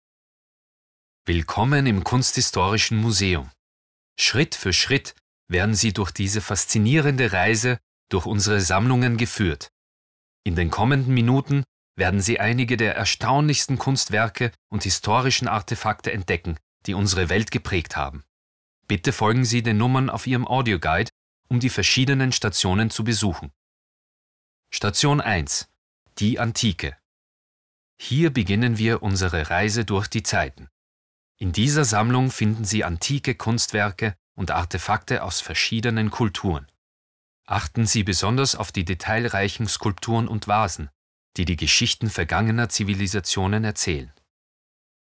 My voice "flows like water" - it's soothing, calm, but can be present and powerful.
Sprechprobe: eLearning (Muttersprache):